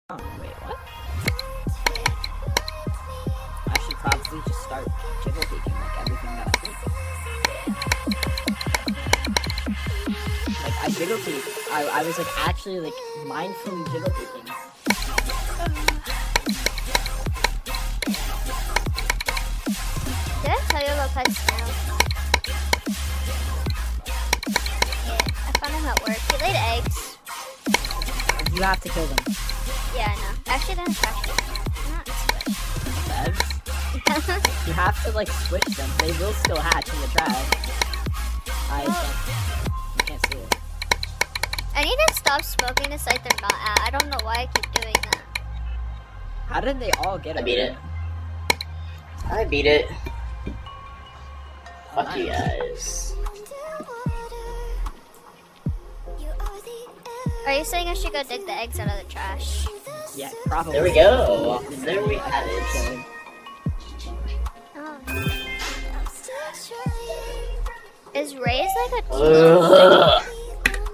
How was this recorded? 700 total attempts, this level made me unhappy. (audio high pitched due to copyright lol).